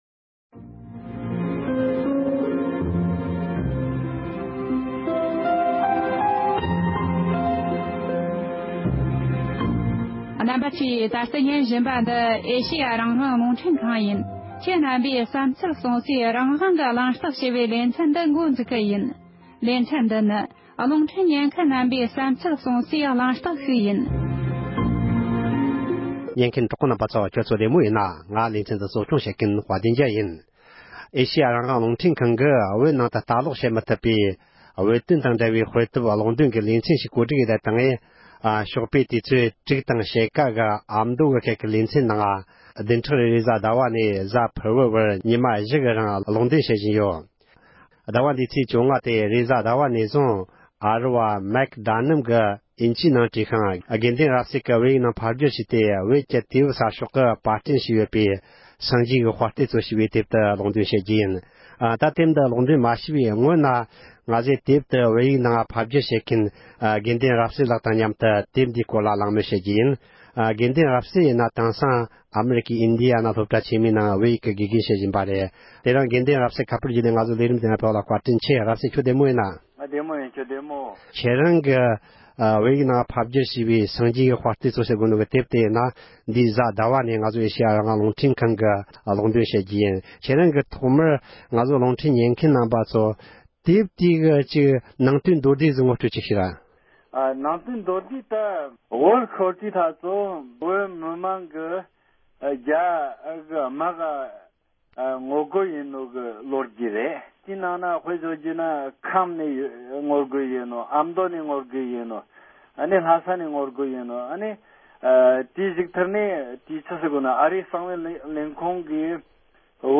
གླེང་མོལ་ཞུས་པ་ཞིག་ལ་གསན་རོགས་ཞུ